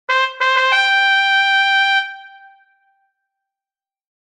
Trumpet Sound 2